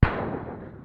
mortar shoot.mp3